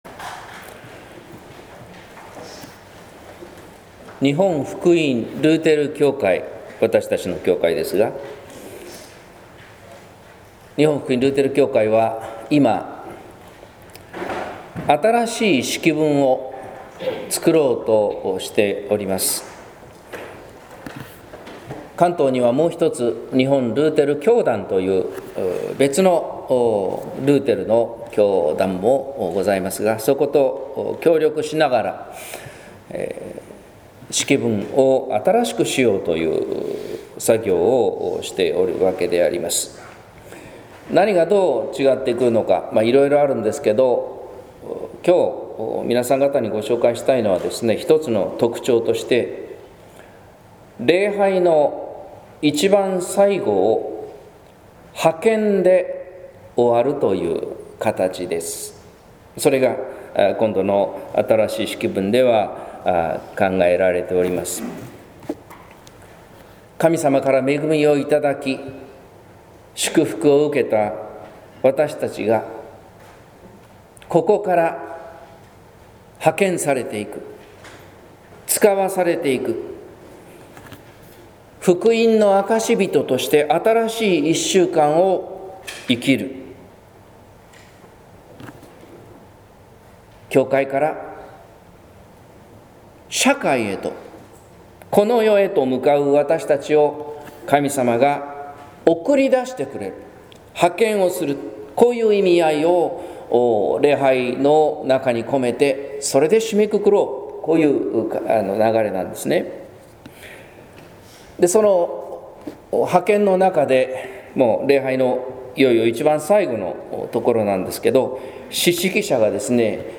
説教「平和の素は愛の粒」（音声版）